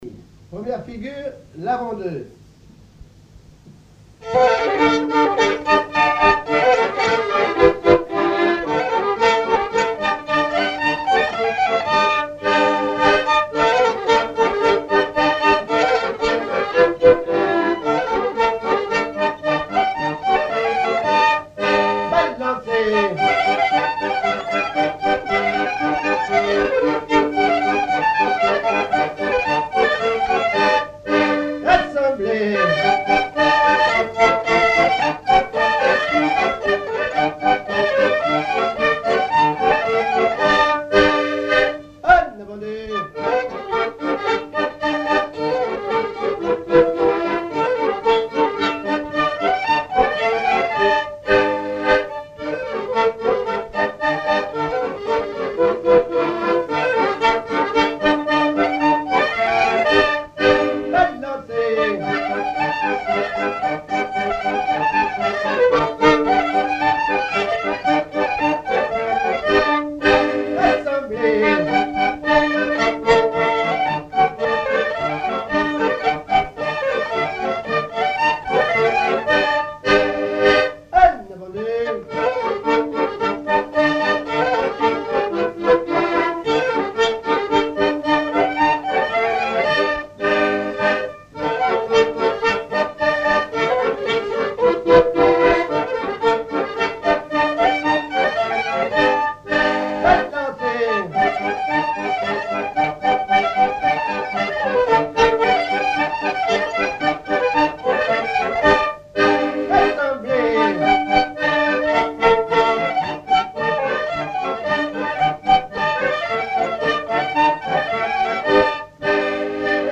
Rochetrejoux
danse : quadrille : avant-deux
Pièce musicale inédite